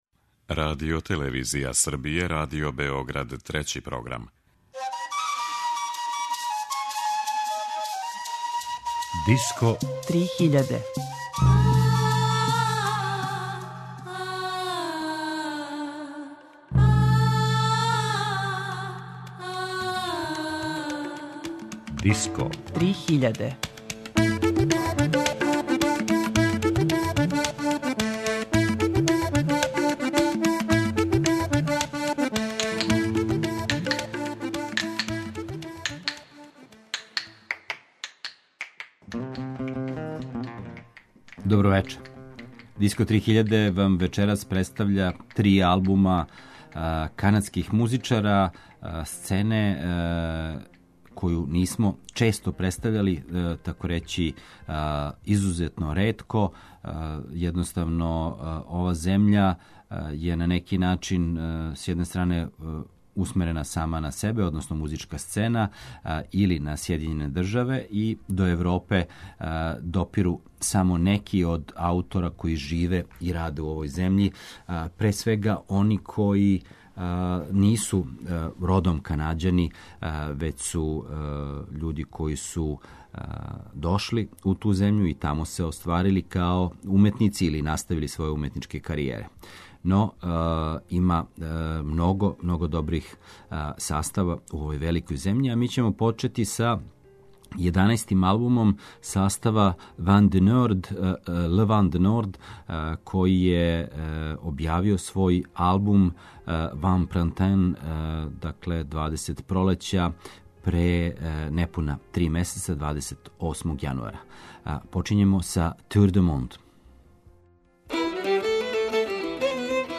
Етно музика Канаде